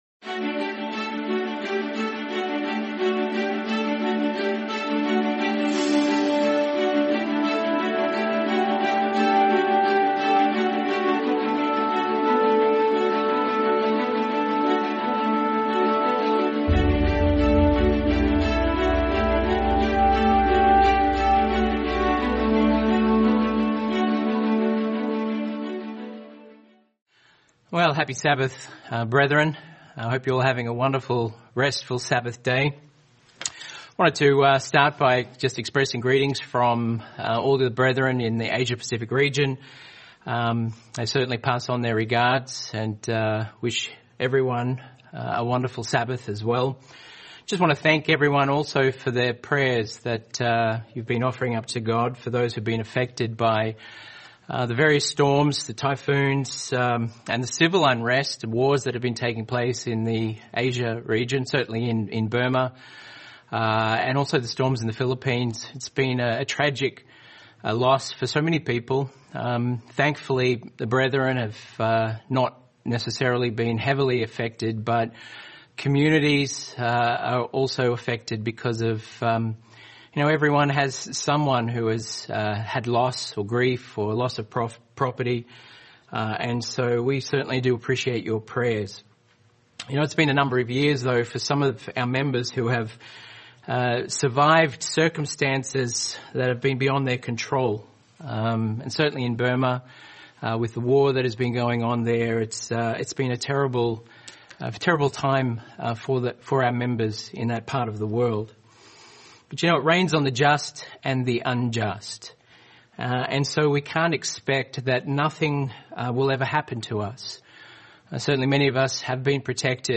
Faith in the Unbelievable | Sermon | LCG Members